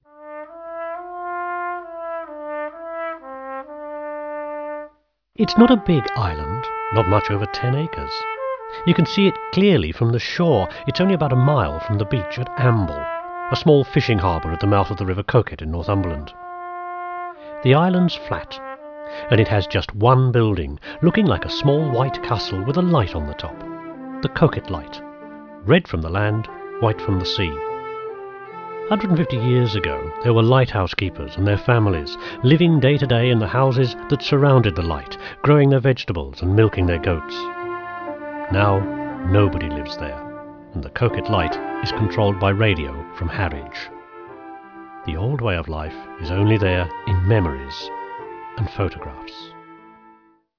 Teeafit Sound & Vision has a sophisicated audio facility, idea for the recording of spoken-word material.
This is from an aural history programme about the Coquet Island Lighthouse off the Northumberland coast, which was originally commissioned from Teeafit Sound & Vision by BBC Radio 4.